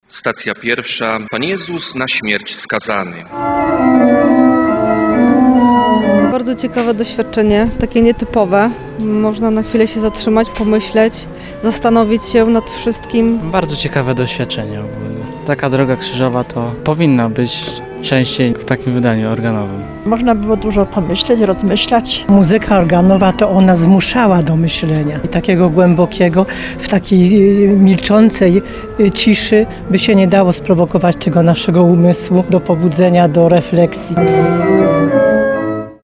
Wielkopostne nabożeństwo w parafii Ducha Świętego miało wyjątkową oprawę.